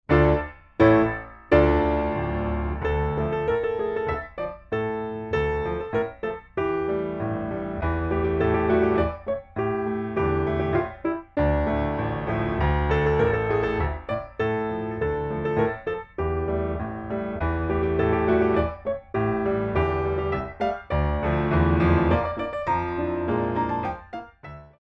Original Music for Ballet Class
Recorded on a Steinway B at Soundscape
4 Count introduction included for all selections
4/4 - 64 with repeat